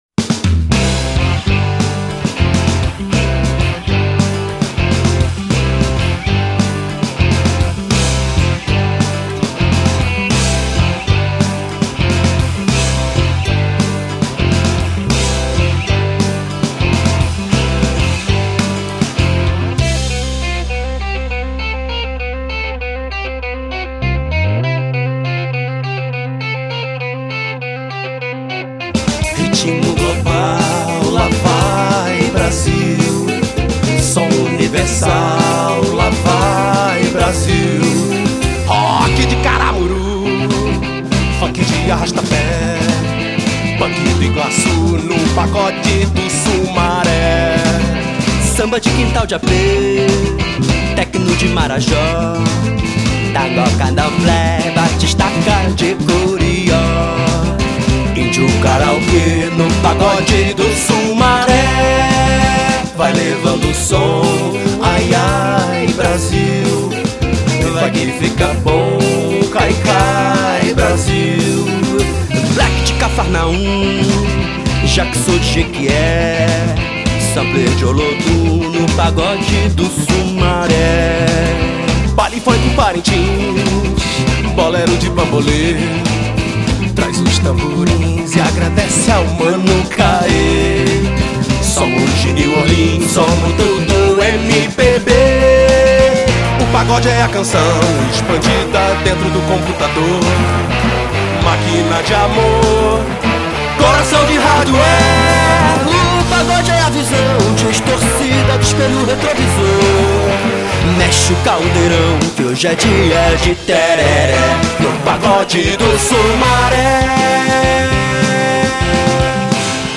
jazz-influenced